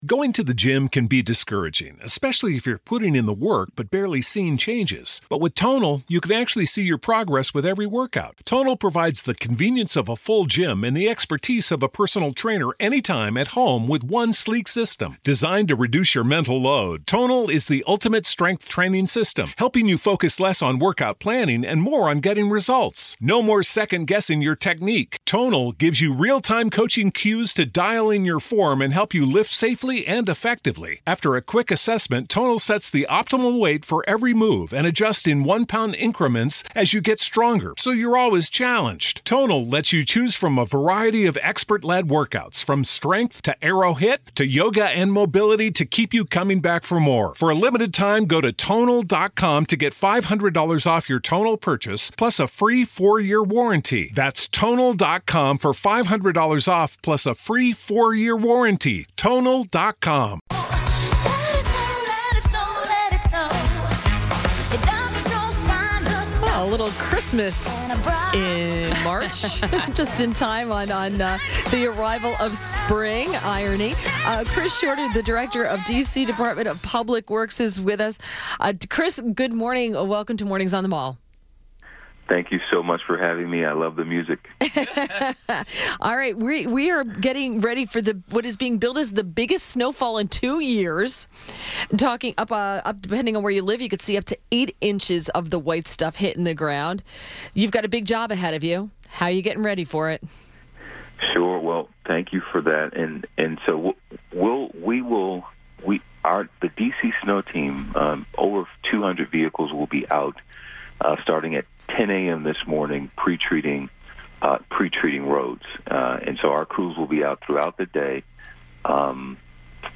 INTERVIEW - CHRIS SHORTER - Director, DC Department of Public Works – discussed the steps D.C. is taking to prepare for the upcoming potential snowfall